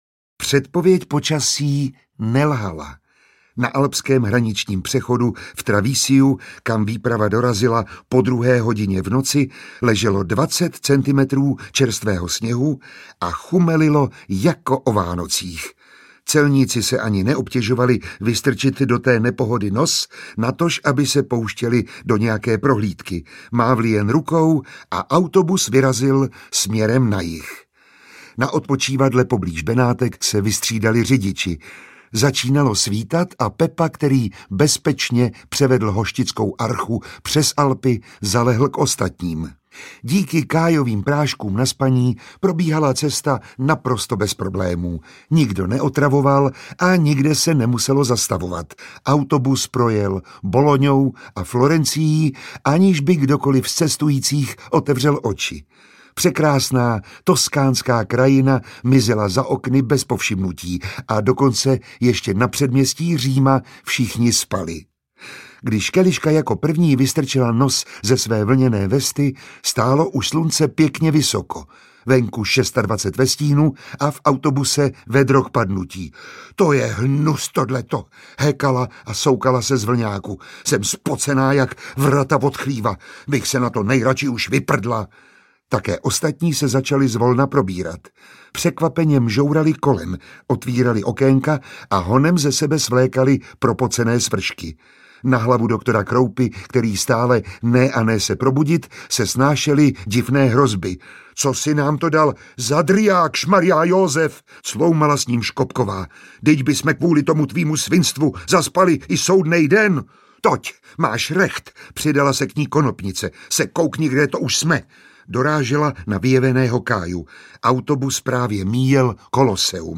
Audiobook
Read: Miroslav Táborský